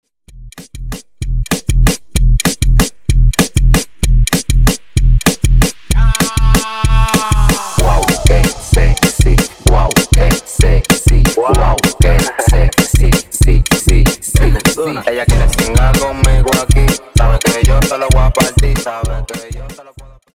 Extended Dirty Intro